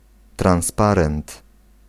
Ääntäminen
IPA : /ˈbænə(ɹ)/
IPA : /ˈbænɚ/